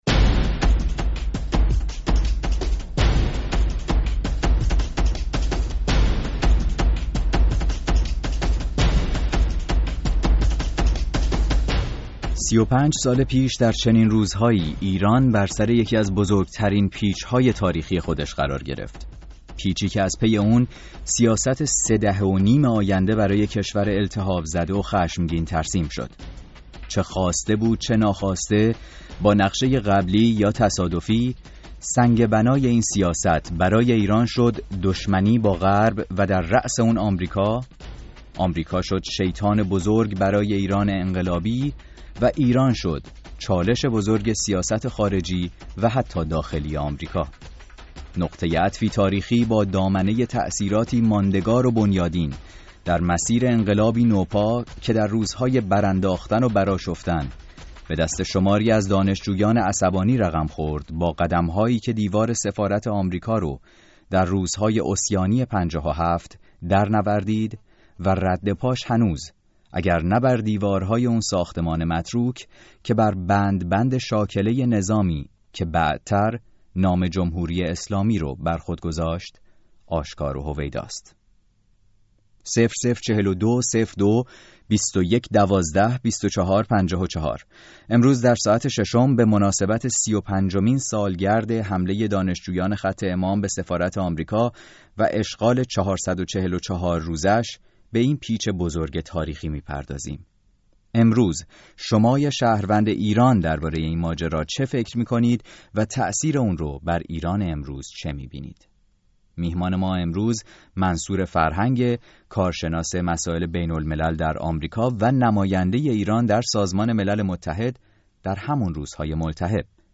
اين هفته برنامه ساعت ششم به مناسبت سی و پنجمين سالگرد گروگانگيری در سفارت آمريکا در تهران، ميزبان مخاطبان راديو فردا و منصور فرهنگ بود که در آن ماه های پر التهاب، نمايندگی ايران در سازمان ملل متحد را بر عهده داشت.